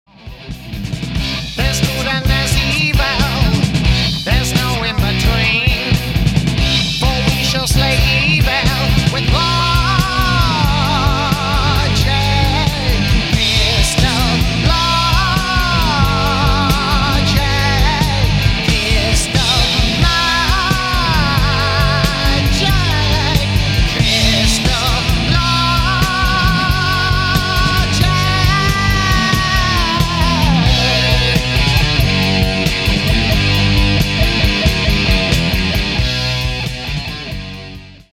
• Качество: 192, Stereo
Metal
heavy Metal